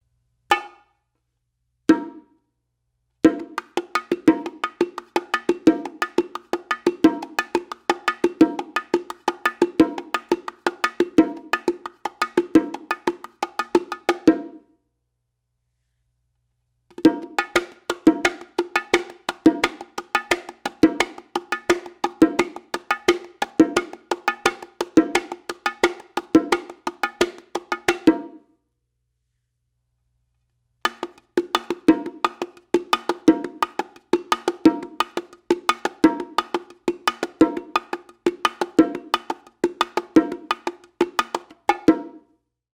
This Bongos convinces with a stunning sound reaching from powerful mids to sharp highs.